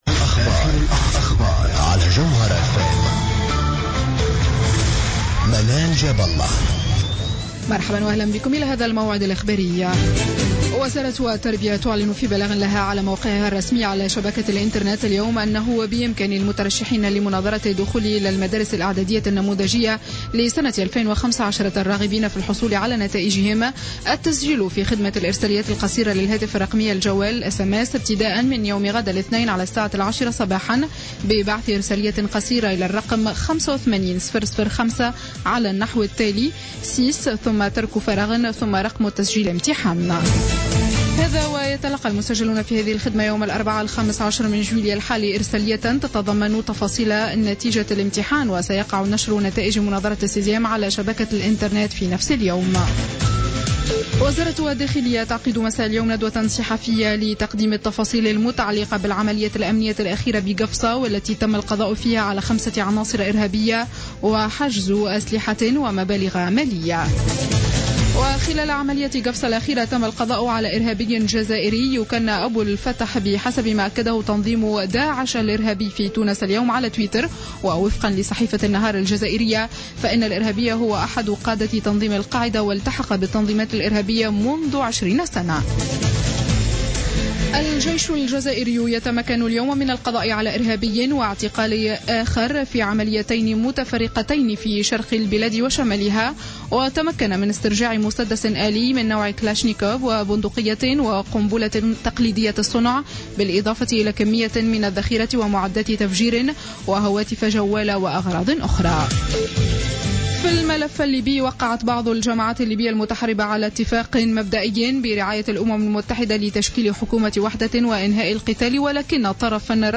نشرة أخبار الخامسة مساء ليوم الأحد 12 جويلية 2015